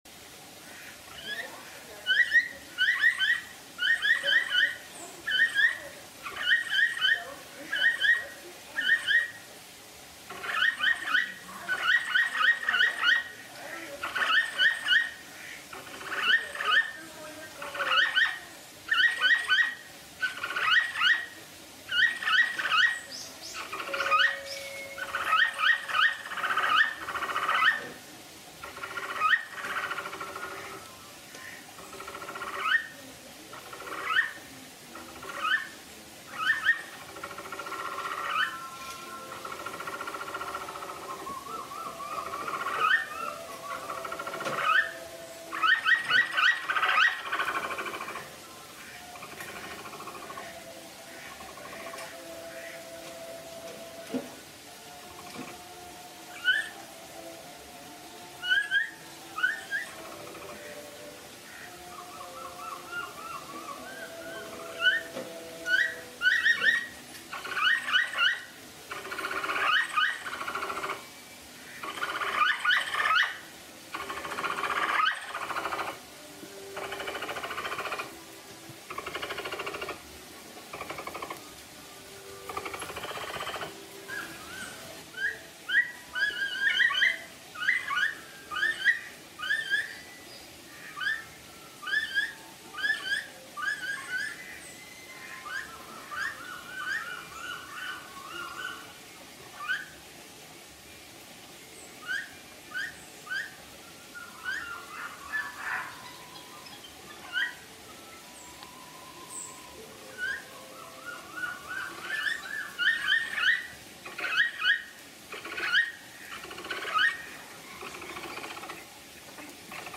На этой странице собраны натуральные записи: от умиротворяющего хруста травы до игривых возгласов в стае.
Звуки капибары: Плач самца капибары (запись из зоопарка)